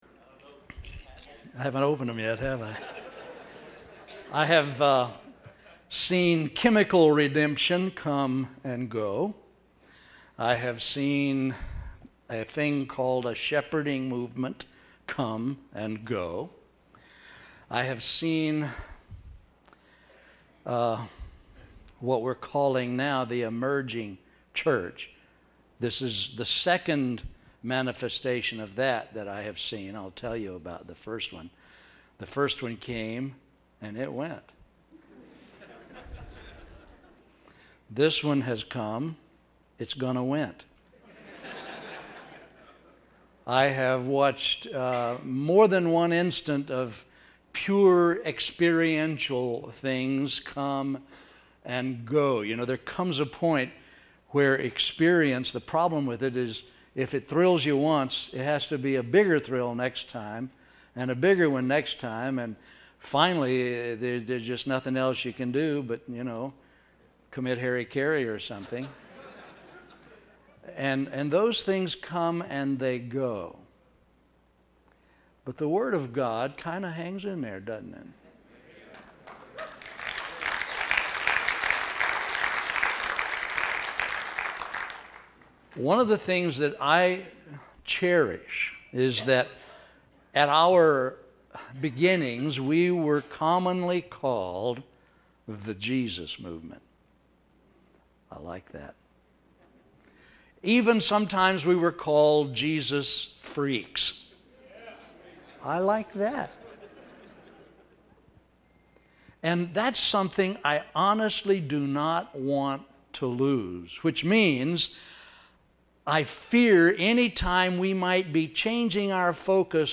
2007 Home » Sermons » Session 3 Share Facebook Twitter LinkedIn Email Topics